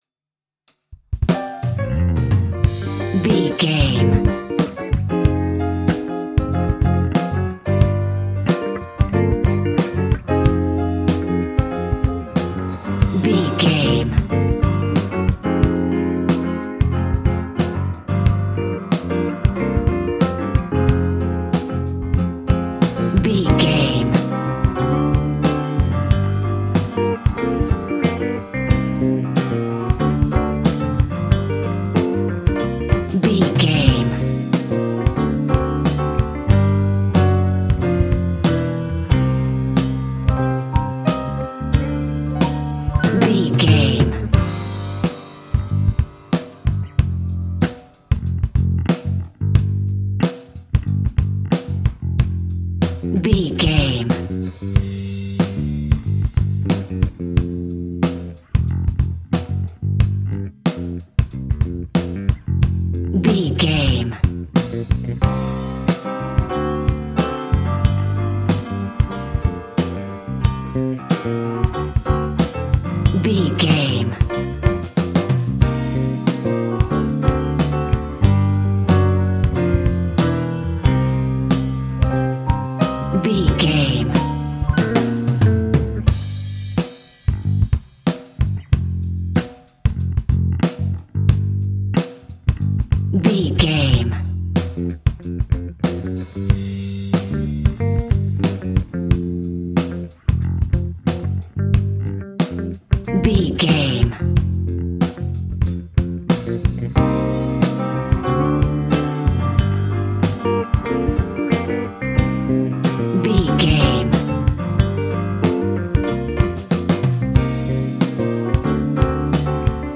Epic / Action
Fast paced
In-crescendo
Uplifting
Ionian/Major
hip hop